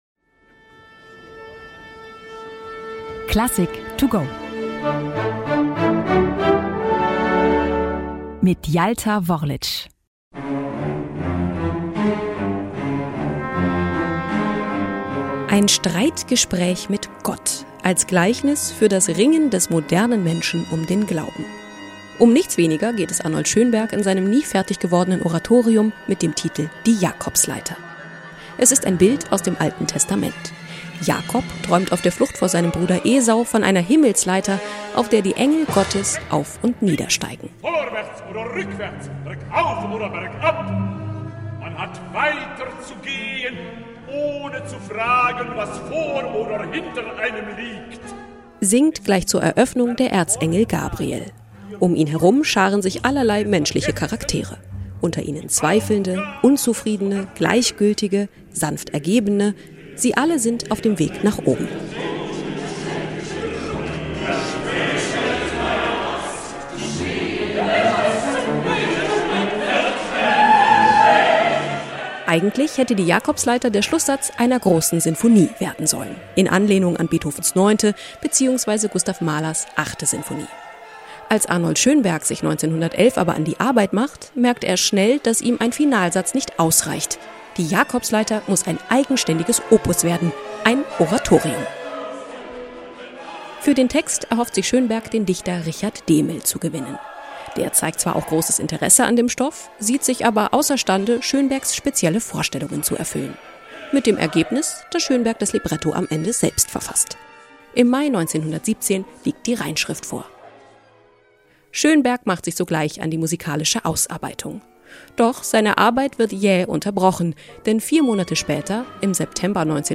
Werkeinführung für unterwegs.